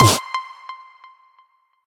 combo_16_power.ogg